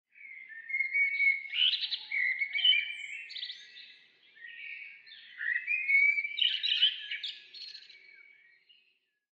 sf_campagne_01.mp3